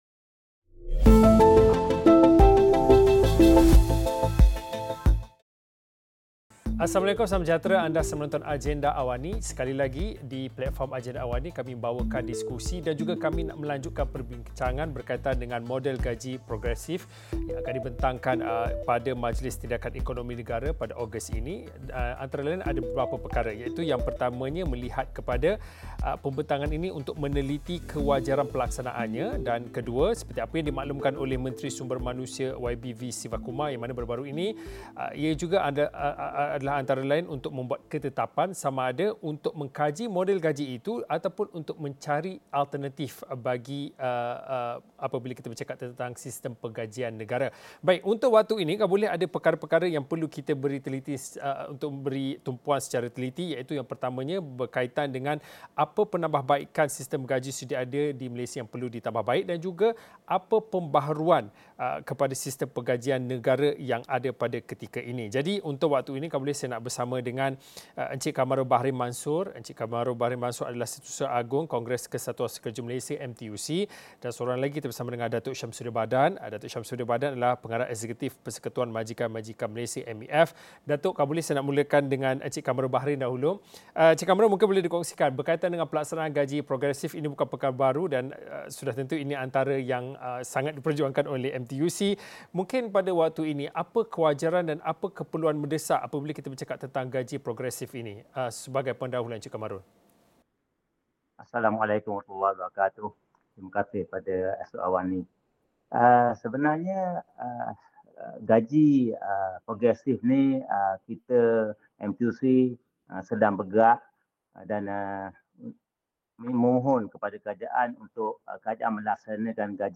Apakah alternatif selain cadangan pelaksanaan Model Gaji Progresif di negara ini dan apa penambaikan yang perlu dilakukan dalam sistem gaji sedia ada? Diskusi 9 malam